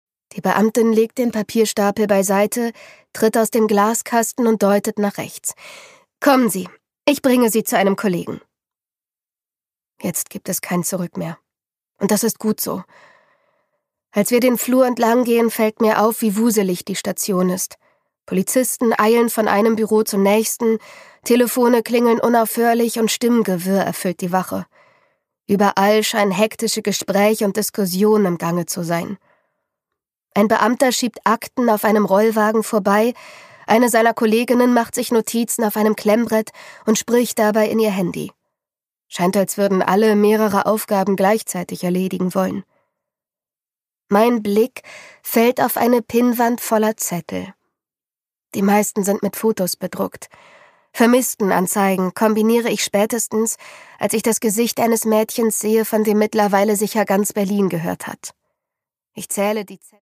Produkttyp: Hörbuch-Download
MP3 Hörbuch-Download